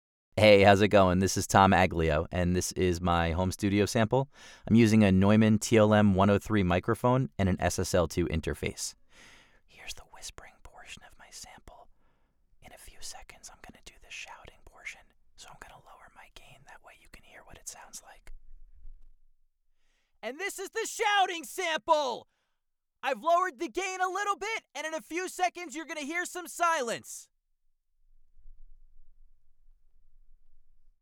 Male
Assured, Authoritative, Bright, Bubbly, Character, Confident, Cool, Corporate, Engaging, Friendly, Gravitas, Natural, Reassuring, Sarcastic, Smooth, Soft, Streetwise, Wacky, Warm, Witty, Versatile, Young
His voice has been described as youthful, modern, real, genuine, conversational, millennial, authentic, bright, warm, enthusiastic, hip, cool, sincere, and fun.
Commercial.mp3
Microphone: Neumann TLM 103
Audio equipment: SSL2 Interface and Whisper Room Booth